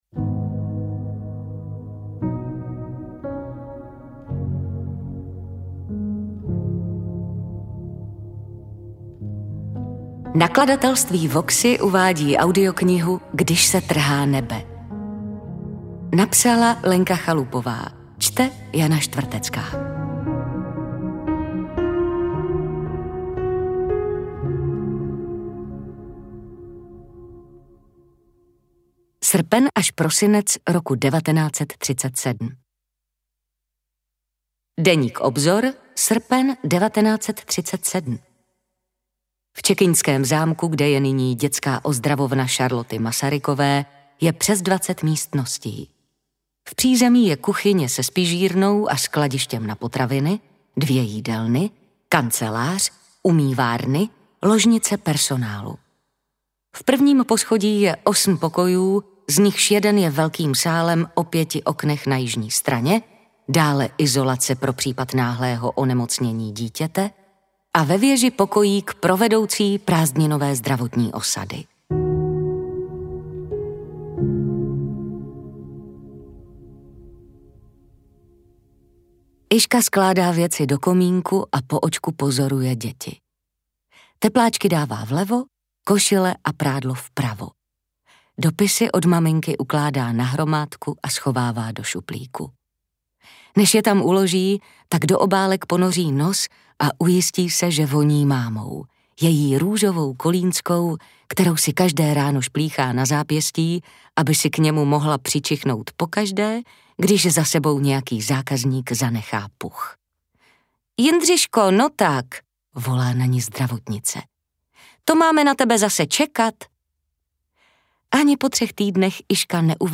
AudioKniha ke stažení, 26 x mp3, délka 10 hod. 23 min., velikost 567,6 MB, česky